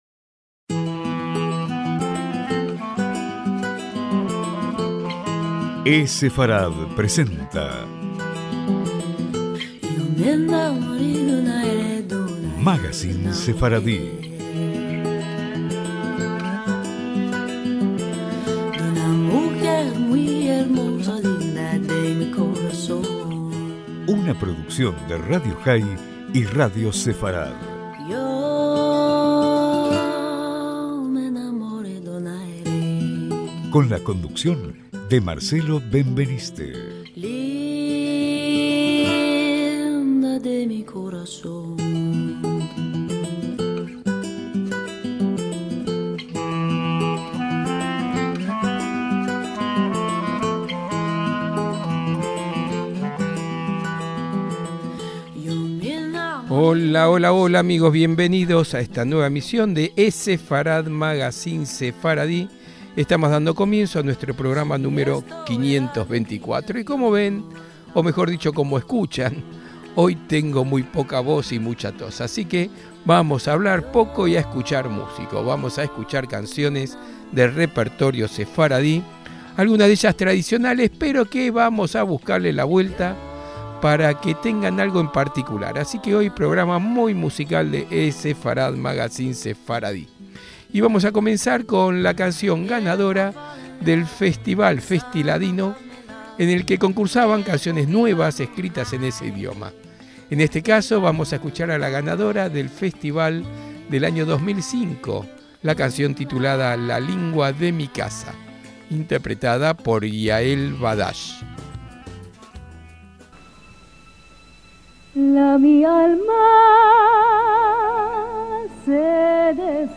ESEFARAD: MAGACÍN SEFARDÍ - Un programa muy musical el de esta semana con canciones tradicionales con un toque no tan tradicional.